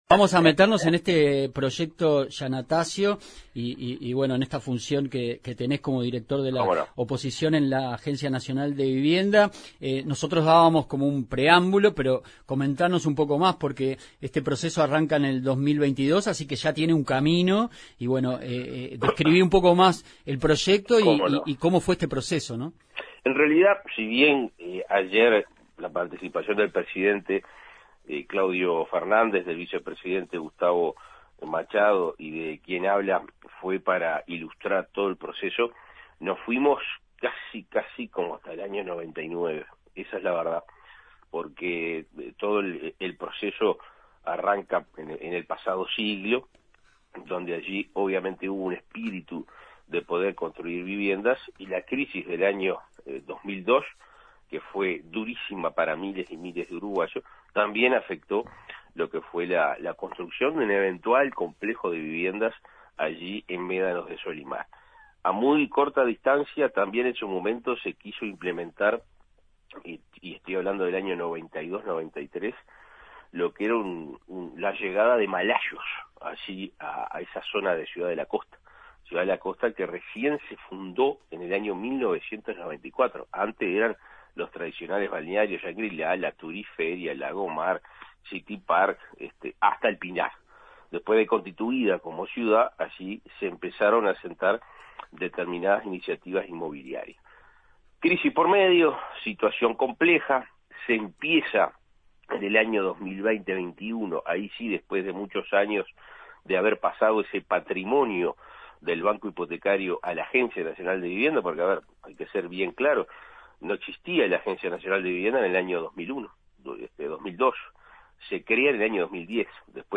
Entrevista a Alfonso Lereté, director de la Agencia Nacional de Vivienda, en representación de la oposición